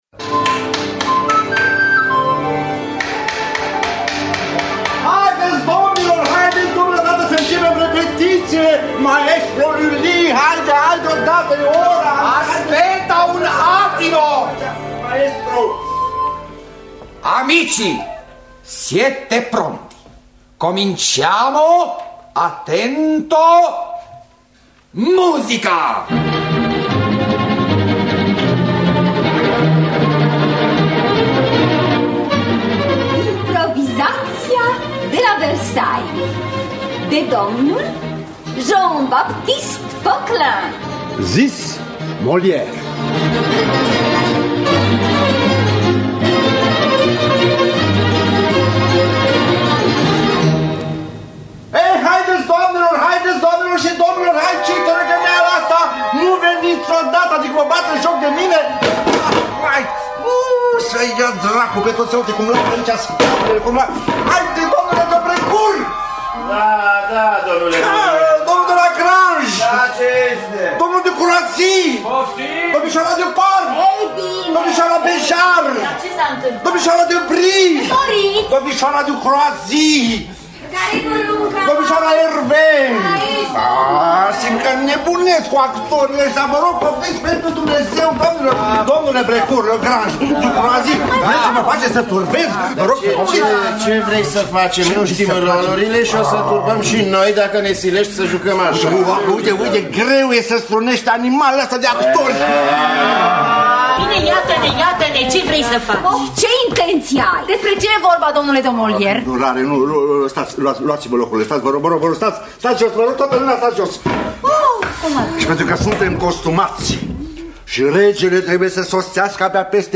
Improvizaţia de la Versailles de Molière – Teatru Radiofonic Online